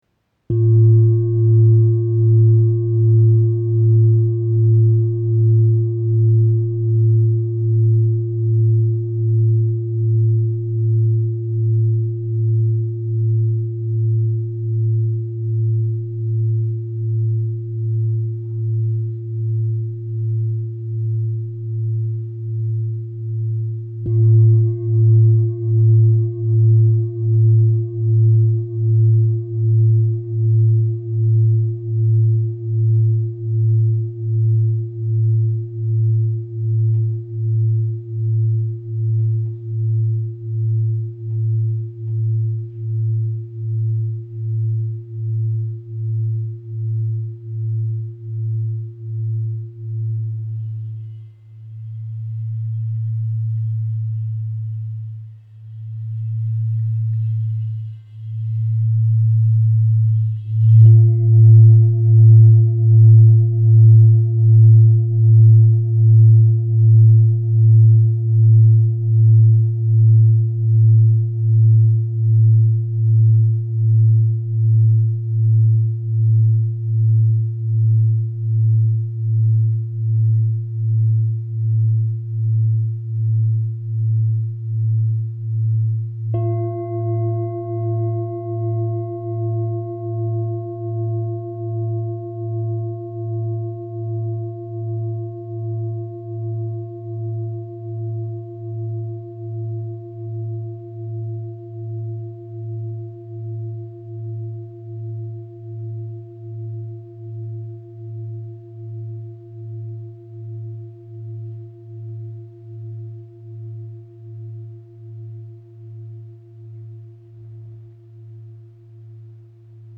Bol chantant 7 métaux • La 109 Hz
Composé de sept métaux martelés à la main par des artisans expérimentés au Népal.
Note : La 109 Hz
Diamètre : 31,5 cm